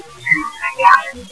On the afternoon of July 7th, 2003 I stopped at the city cemetary in Higbee Missouri
The following EVP ghost - spirit files are from that recording session.
This whispery but loud spirit voice clearly states his full name.